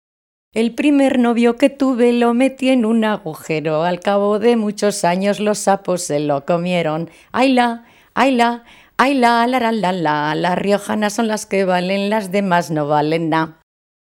Clasificación: Cancionero
Lugar y fecha de recogida: Calahorra, 13 de abril de 2003
Las chicas de Baños de Río Tobía de hace varias décadas se juntaban en las fiestas para cantar juntas en corro o agarradas entre sí, algo habitual en el ambiente festivo de cualquier pueblo de La Rioja.